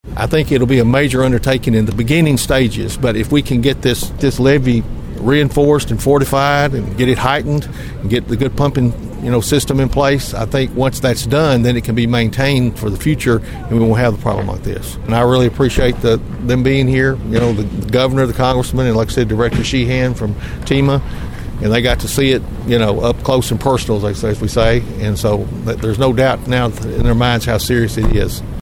Mayor Carr said he feels the problem in Rives can be corrected, which would eliminate the fear of flooding for those living in the community.(AUDIO)